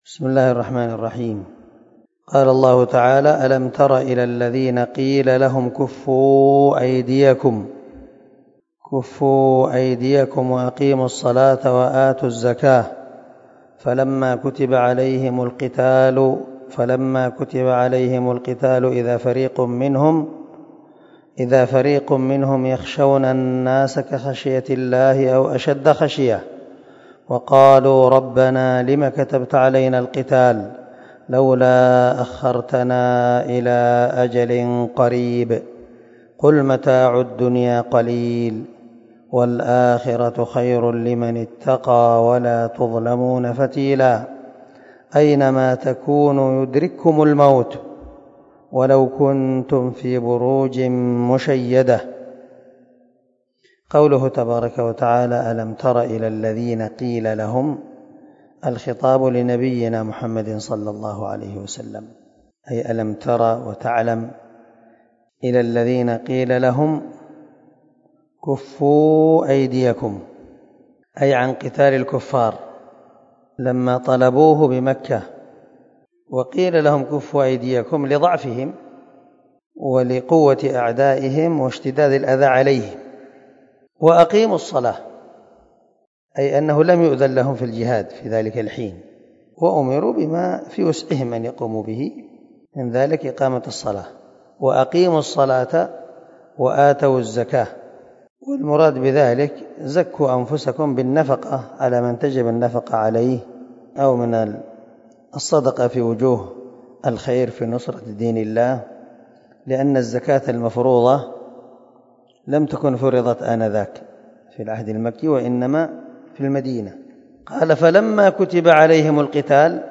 282الدرس 50 تفسير آية ( 77 ) من سورة النساء من تفسير القران الكريم مع قراءة لتفسير السعدي
دار الحديث- المَحاوِلة- الصبيحة.